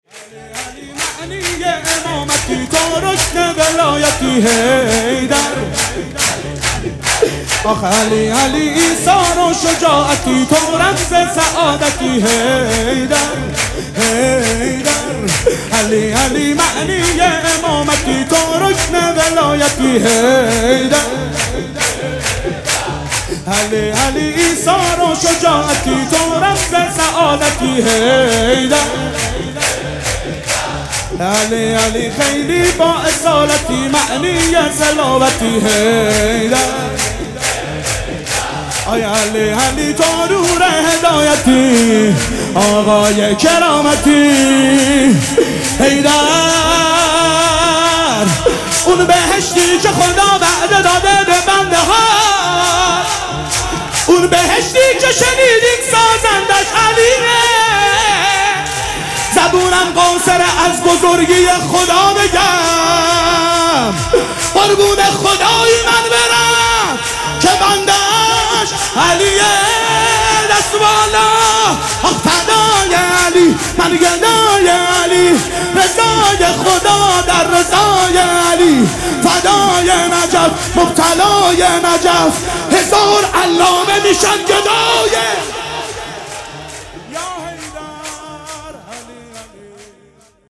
حسینیه ریحانة‌الحسین (سلام‌الله‌علیها)
سرود
جشن عید غدیرخم